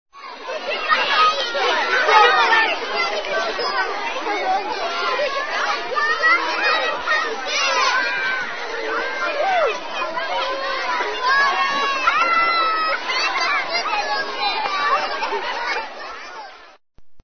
PATI ESCOLA
PATI ESCOLA - Tono movil - EFECTOS DE SONIDO
Tonos gratis para tu telefono – NUEVOS EFECTOS DE SONIDO DE AMBIENTE de PATI ESCOLA
Pati_escola.mp3